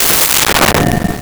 Door Metal Slam 03
Door Metal Slam 03.wav